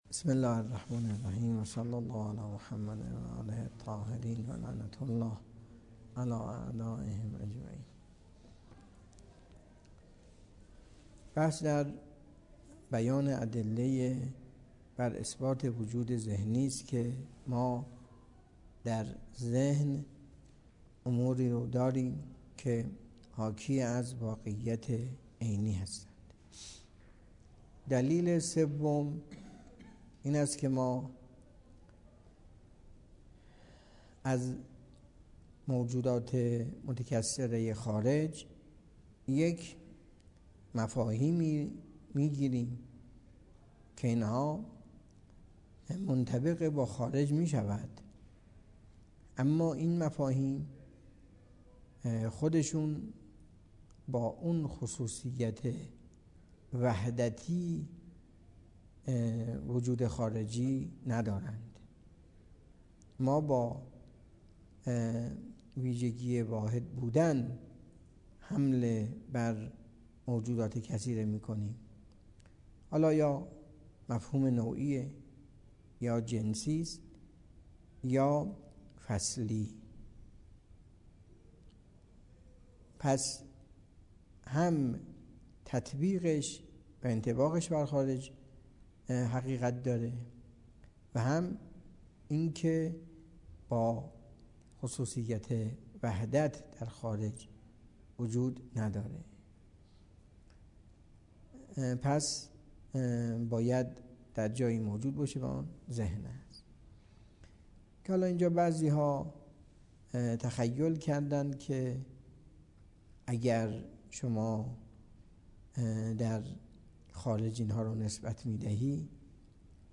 درس فلسفه اسفار اربعه آیت الله تحریری 97.10.17